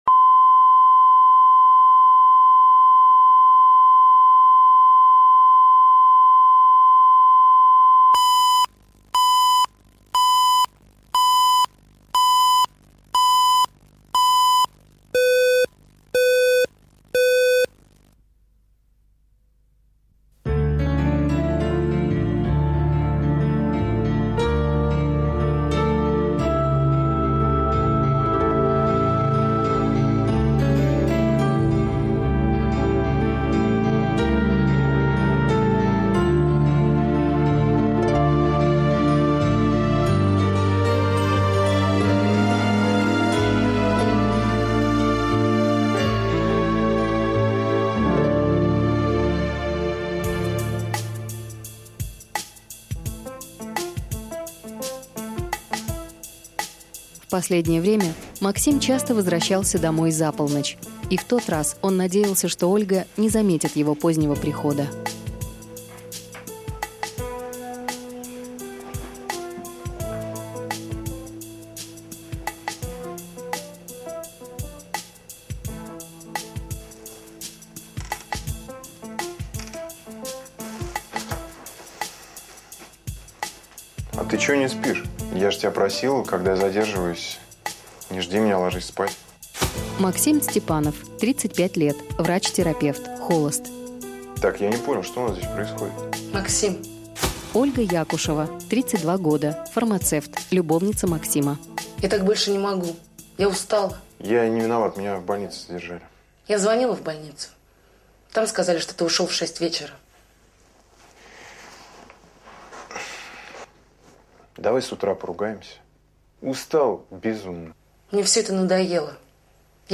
Аудиокнига Первая и последняя | Библиотека аудиокниг